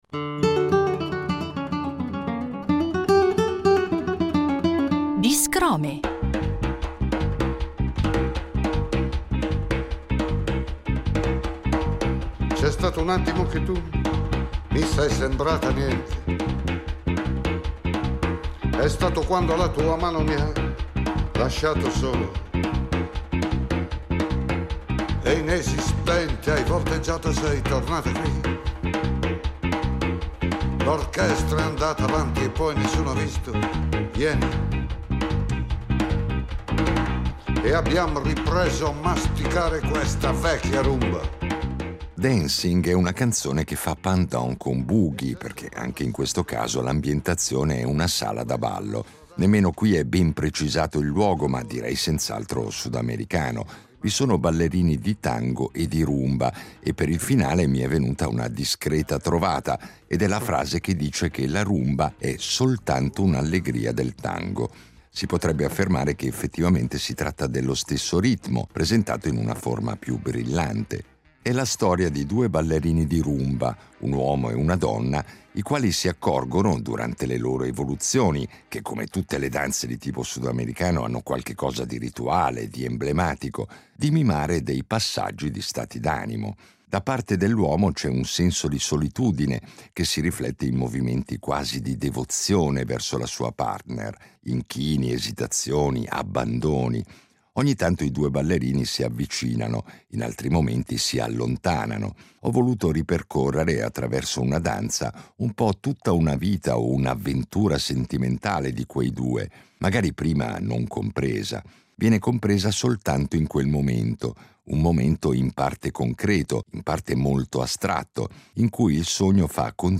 Un’artista che il nostro pubblico conosce bene perché ha collaborato spesso e volentieri con noi, e che negli anni ha sviluppato un percorso artistico eclettico, caratterizzato da una voce raffinata e da una predilezione per progetti che fondono diverse discipline, come il teatro e la poesia.